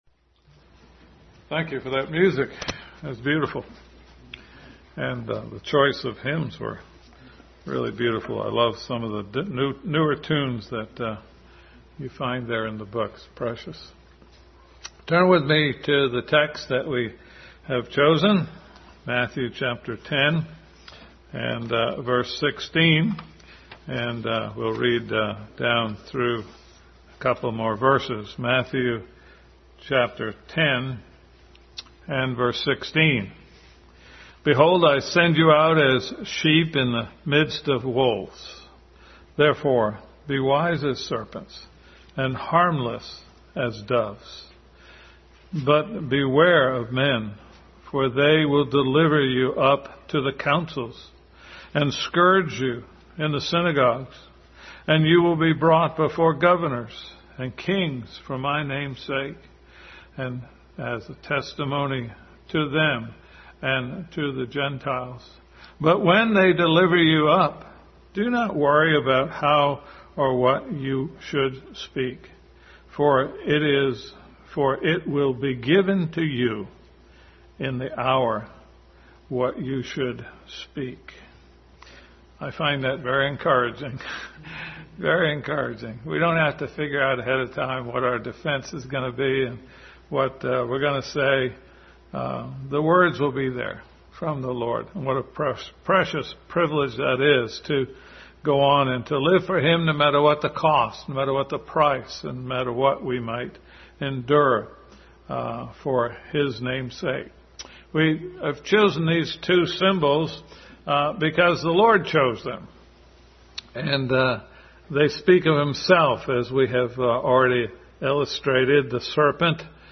Family Bible Hour Message.
Harmless as Doves Passage: Matthew 10:16-19, Ephesians 5:15-17, 5:22-33, Mark 10:13 Service Type: Family Bible Hour Family Bible Hour Message.